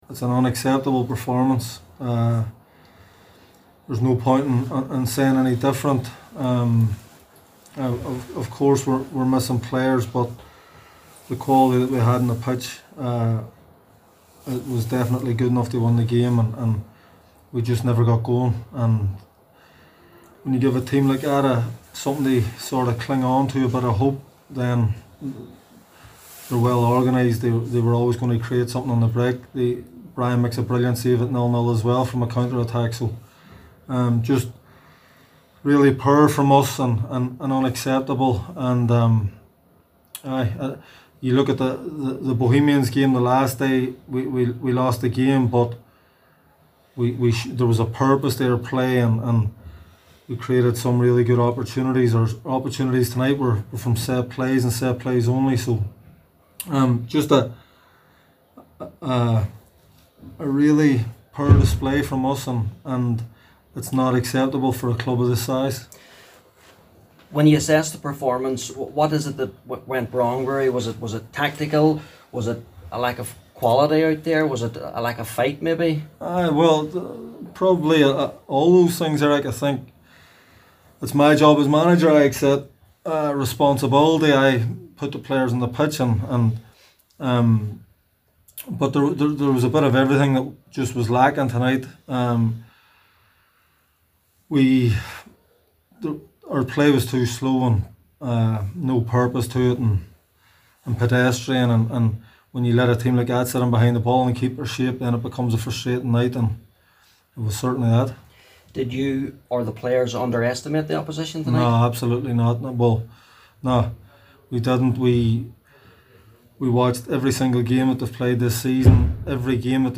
Derry manager Higgins spoke to the press after the game and called the performance as “unacceptable”…
ruaidhri-higgins-reaction-edited-mp3.mp3